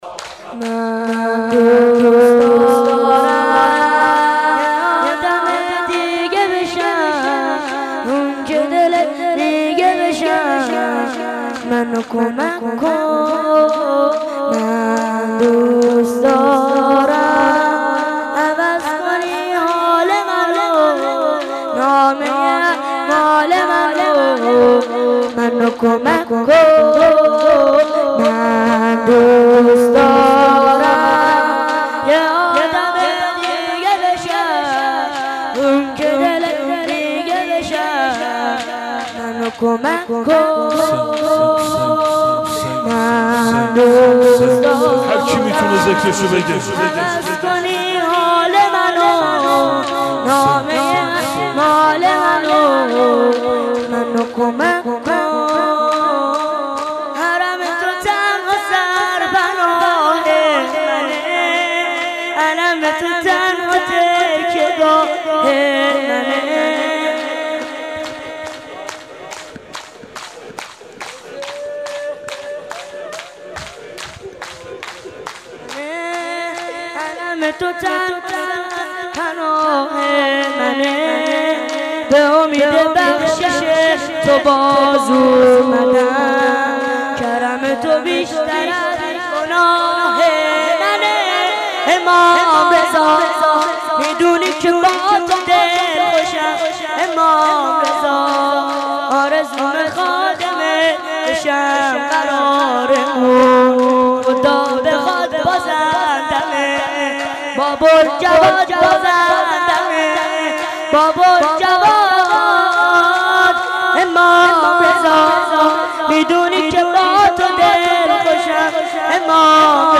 شهادت امام رضا(ع) صفر 98